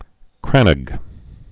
(krănəg)